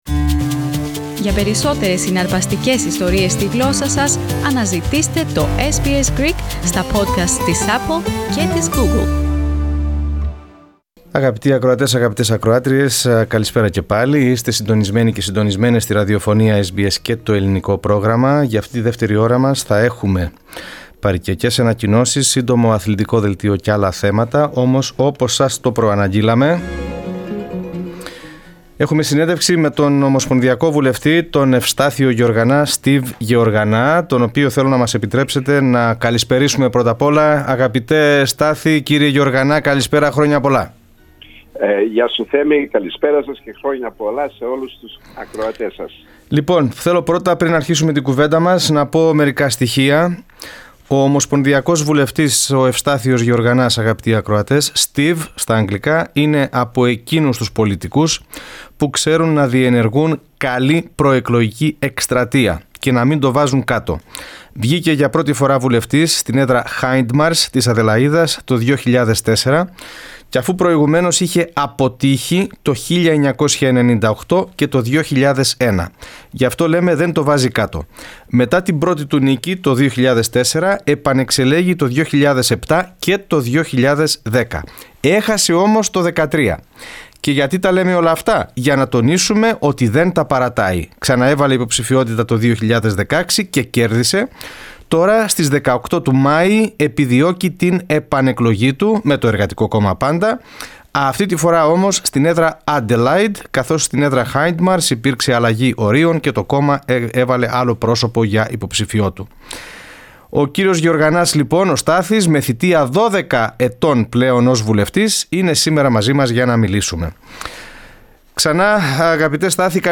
Greek Australian federal MP, Steve Georganas spoke to SBS Greek for the Labor Party's plans to tackle stagnant salaries, health care problems and to help Australians buy their first home through changes in Negative Gearing.
Press Play on the image to hear the full interview in Greek.